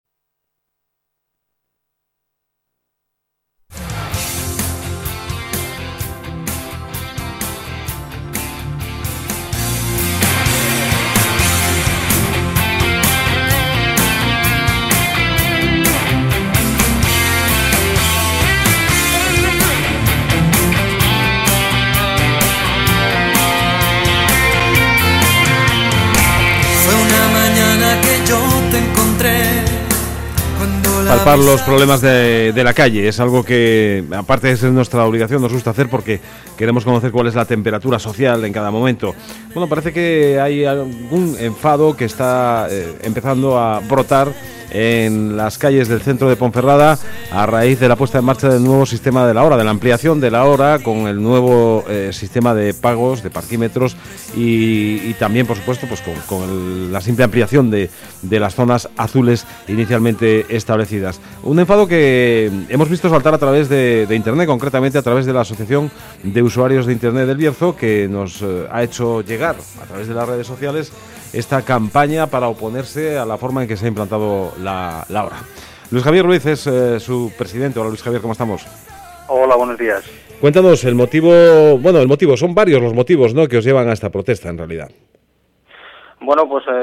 Ultima Entrevista: